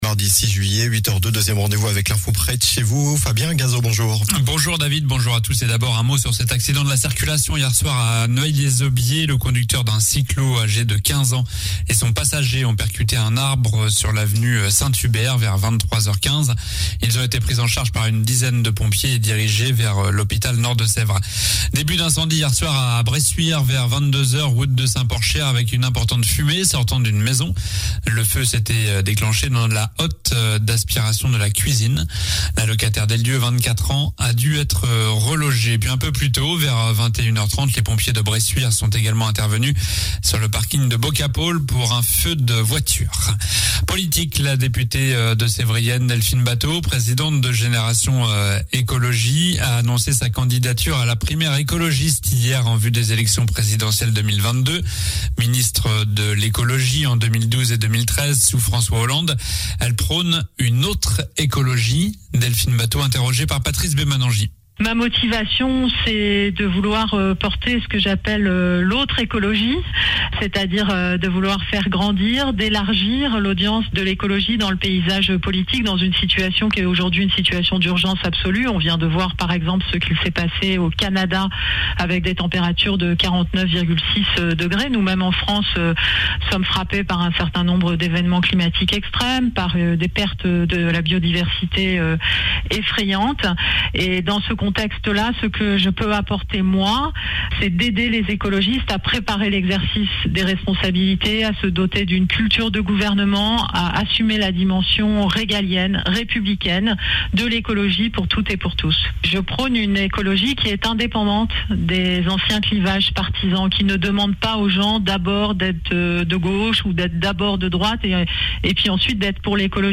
Journal du mardi 06 juillet (matin)